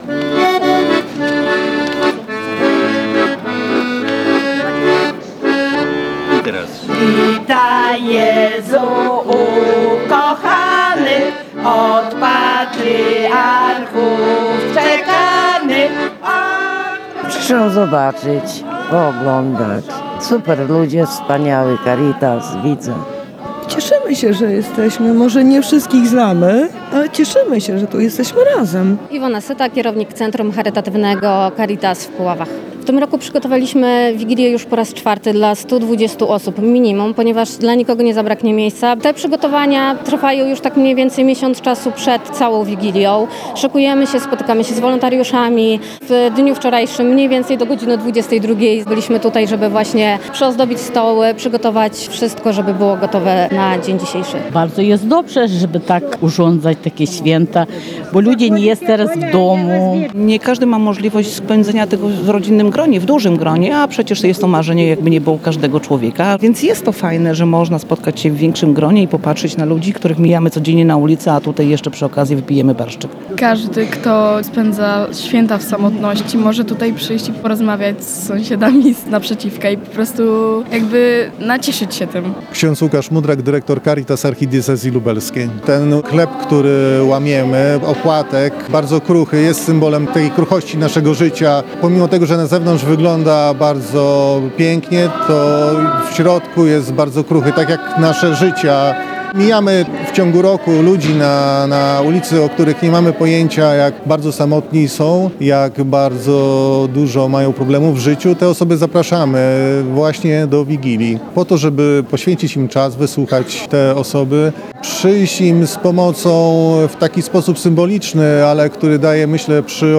O odpowiedni nastrój tego wydarzenia zadbał Zespół Śpiewaczy „Babeczki” z gminy Nowodwór.